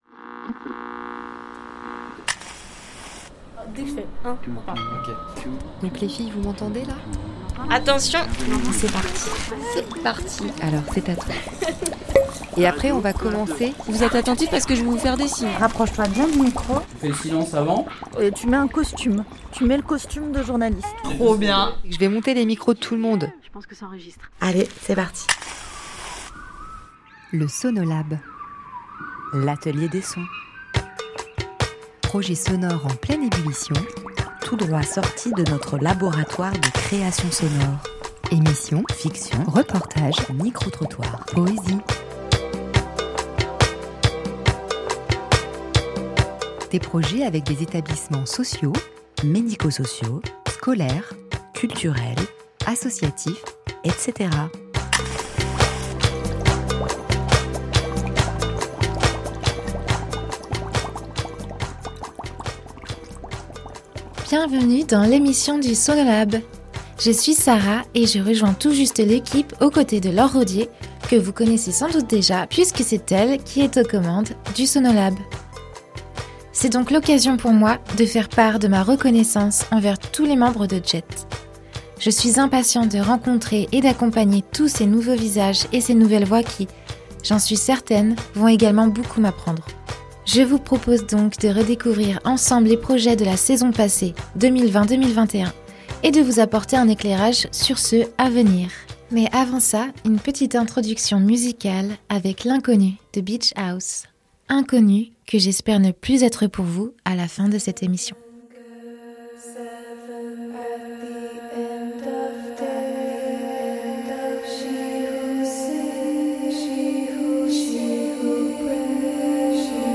Pendant les vacances le répondeur du Sonolab a reçu beaucoup de messages ! Une belle façon de faire le bilan de la saison 2020-2021 et de présenter les projets à venir.